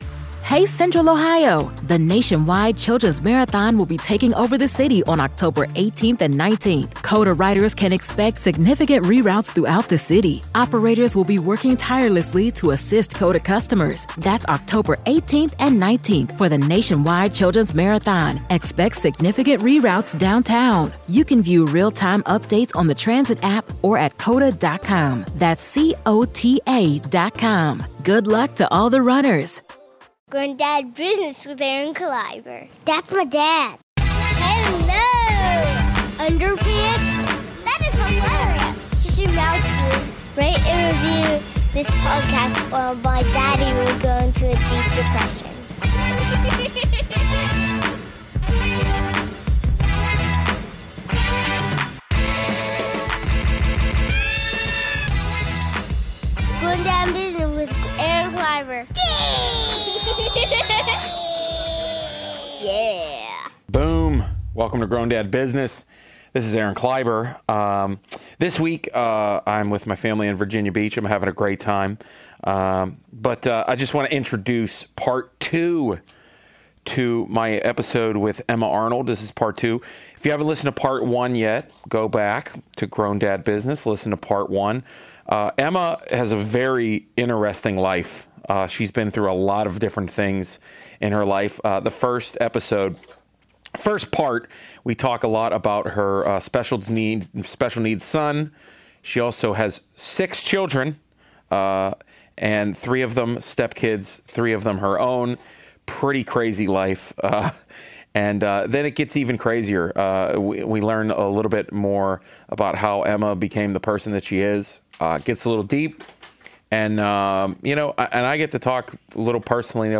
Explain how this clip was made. During the Limestone Comedy Festival